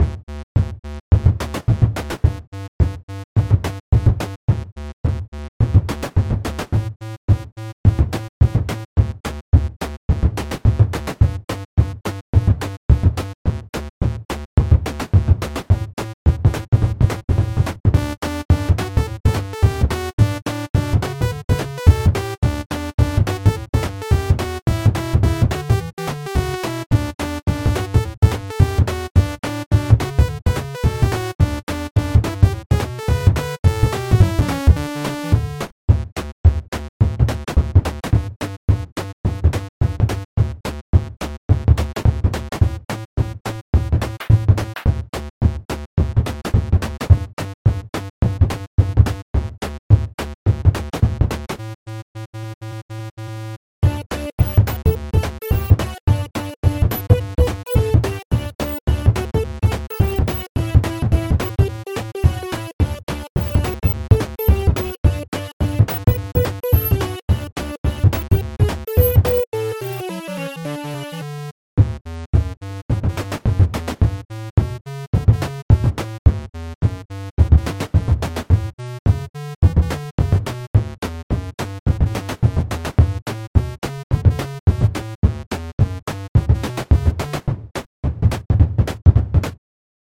This particular song uses my favorite drum patterns,
It definately has a much more happy sound than my last song.